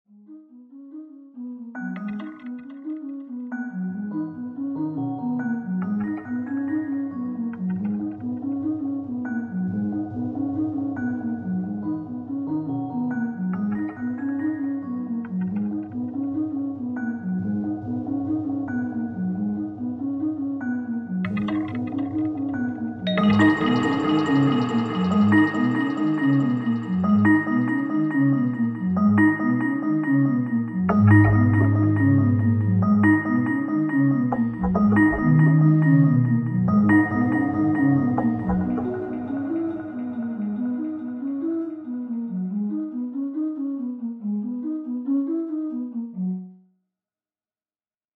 trying to create ambient piece